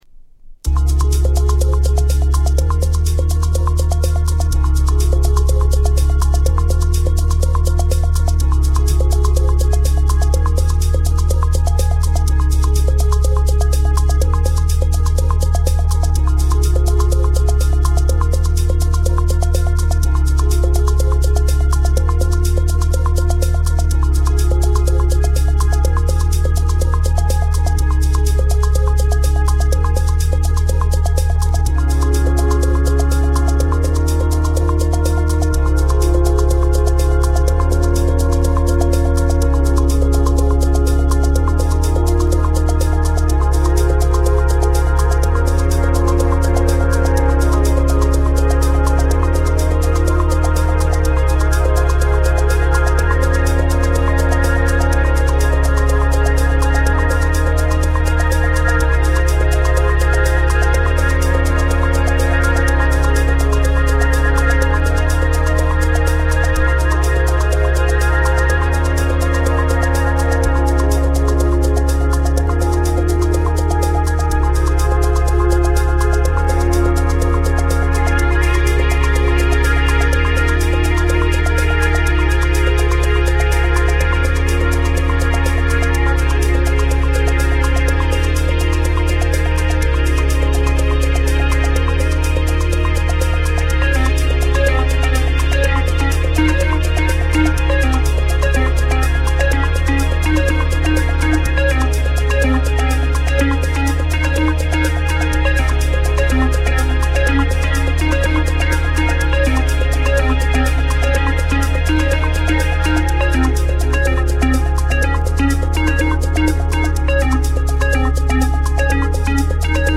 ジャンルの狭間で生まれる魅力的な作品！